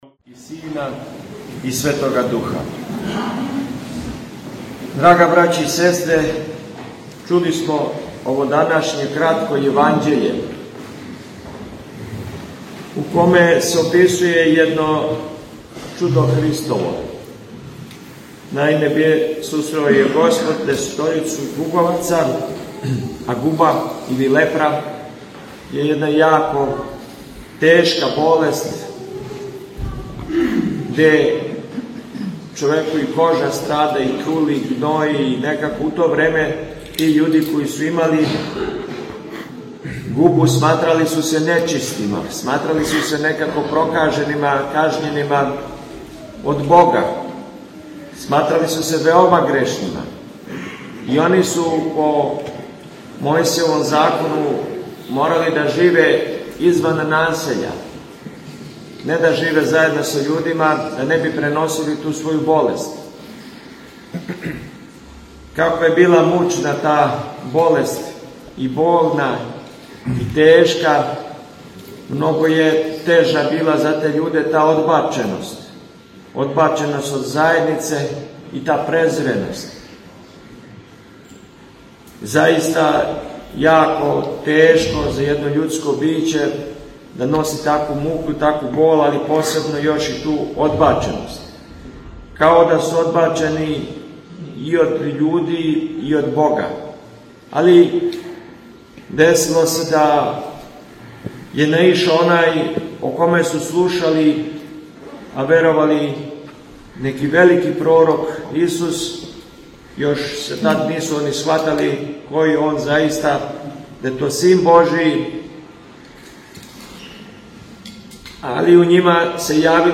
У оквиру серијала „Са амвона“, доносимо звучни запис беседе коју је Његово Преосвештенство Епископ липљански г. Доситеј, викар патријарха српског, изговорио у недељу двадесет и осме седмице по Педесетници, 8/21. децембра 2025. године. Епископ Доситеј је беседио на светој Литургији у Световазнесенском храму у Београду.